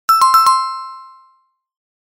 正解・不正解の音_2（正解・ピンポン音_2）